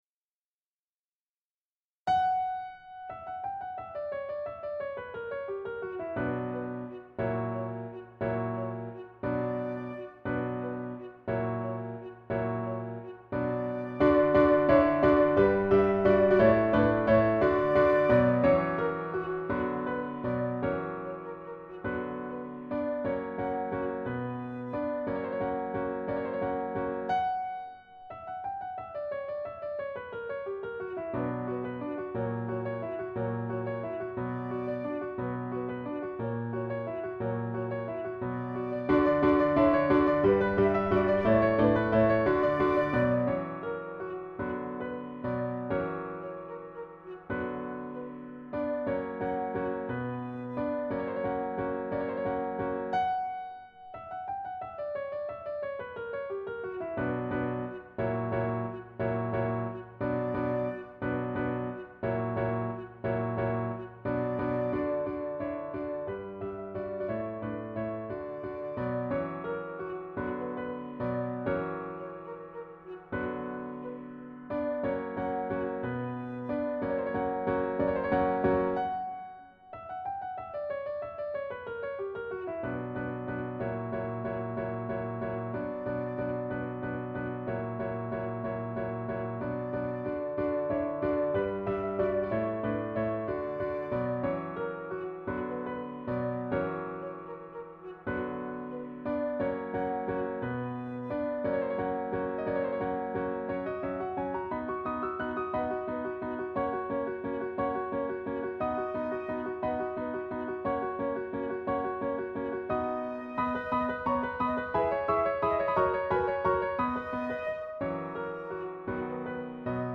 Opus 83 - Chants (3) pour voix et piano - 1810
Quatre strophes répètent la mélodie en si mineur, la cinquième correspondant au sens des mots, la reprend et conclut en Majeur.
Opus083_DreiGesangeMitKlavierbegleitung2_Sehnsucht.mp3